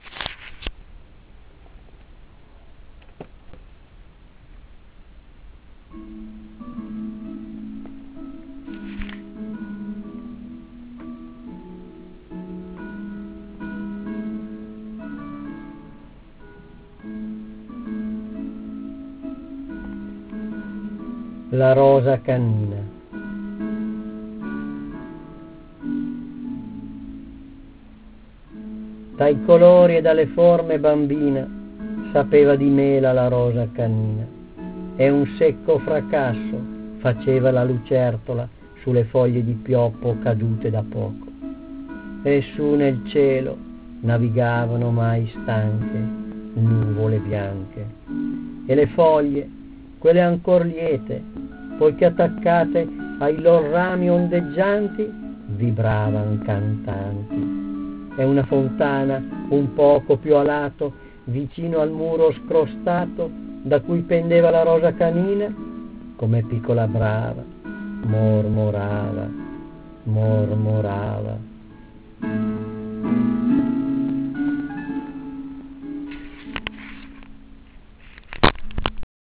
La poesia sonora si collega per un verso alla musica e per un altro al teatro, da un lato abbina il testo poetico ad un brano musicale, dall'altro sfrutta la sonorità del linguaggio.